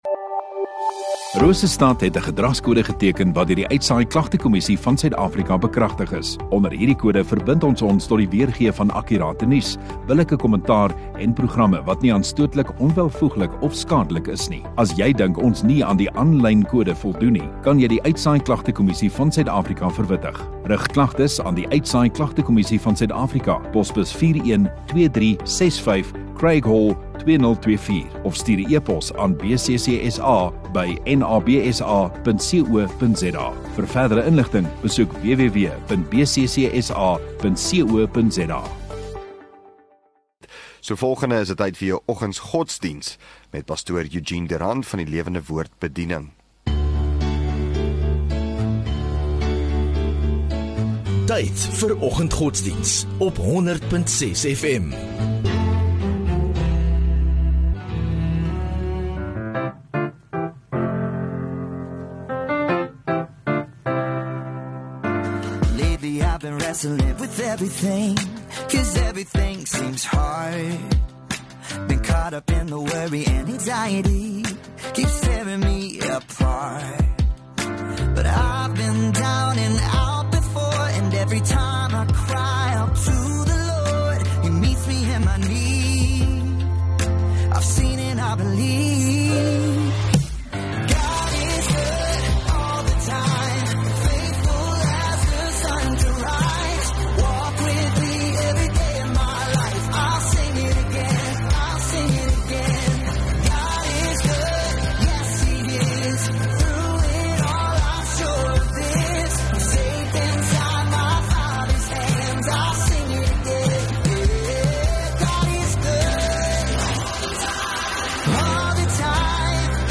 14 Feb Vrydag Oggenddiens